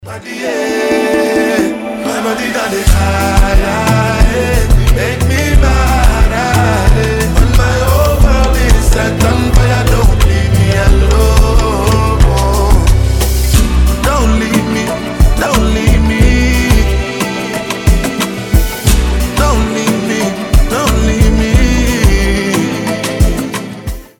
• Качество: 320, Stereo
африканские